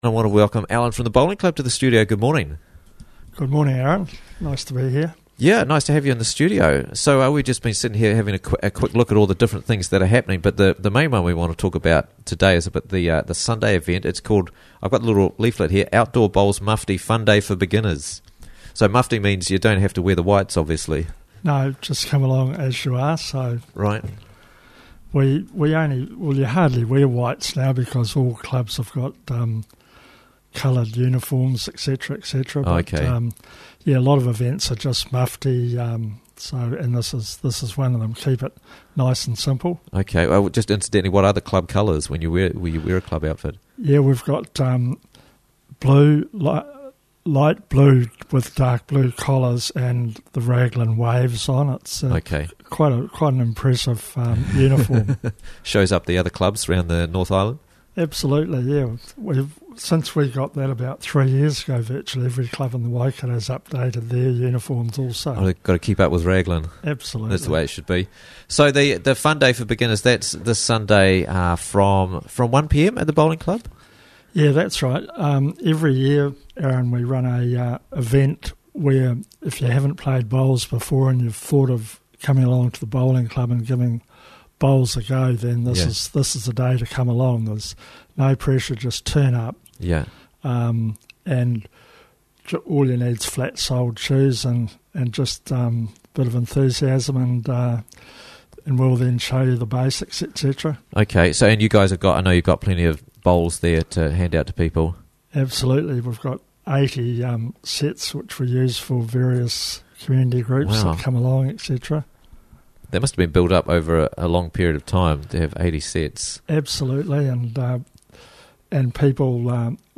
Bowling Beginners Day Sunday - Interviews from the Raglan Morning Show